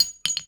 weapon_ammo_drop_02.wav